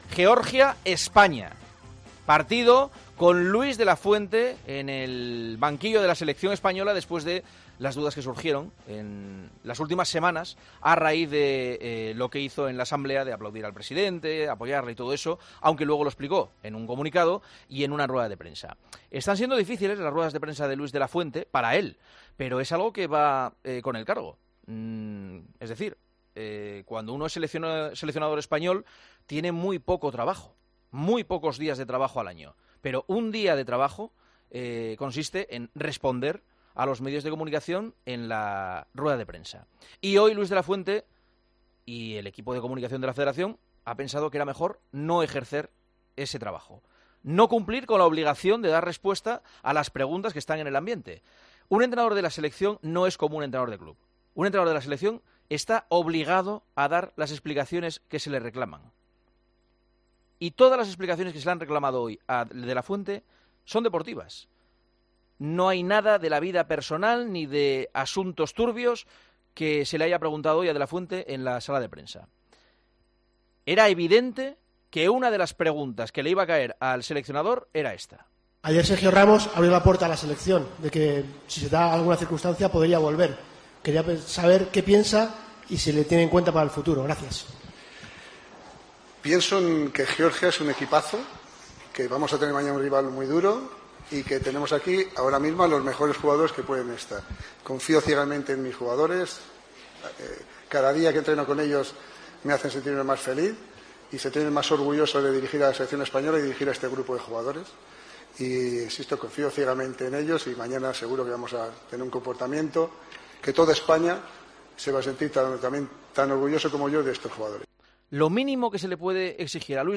Escucha este fragmento de El Partidazo de COPE en el que Juanma Castaño critica la actitud de Luis de la Fuente en rueda de prensa